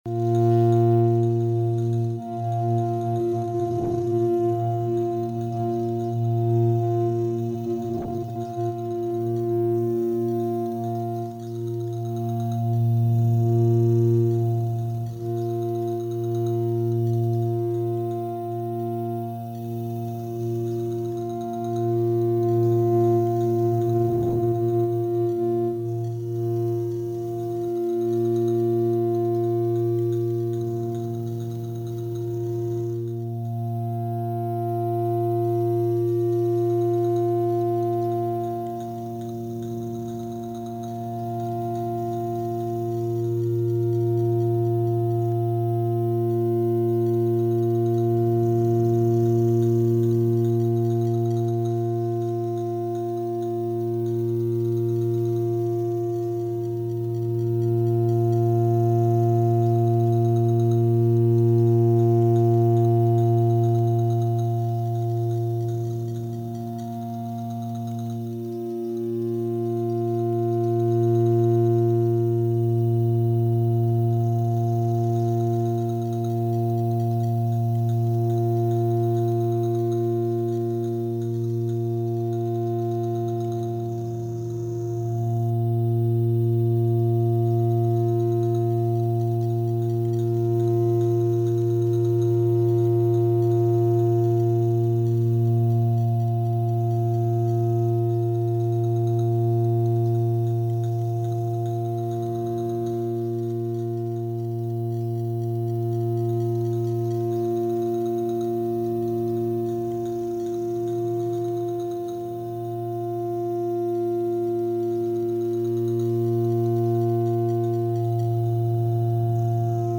dark ambient and unconventional music